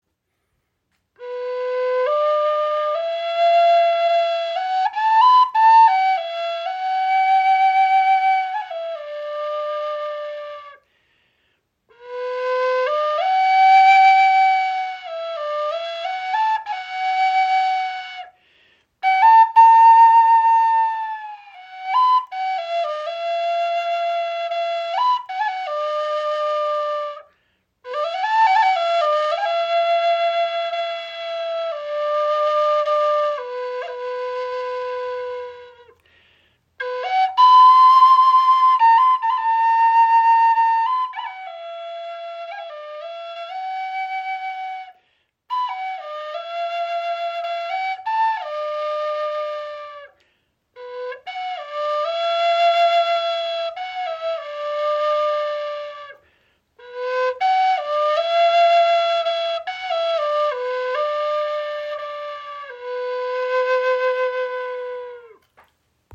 EarthTone Spirit Flöten | Handgefertigt | hohes C in 432 Hz
Unsere EarthTone Spirit Flöte in hohes C-Moll (432 Hz) ist handgefertigt aus einem Stück spanischer Zeder. Das weiche Holz verleiht ihr eine warme, resonante Klangstimme.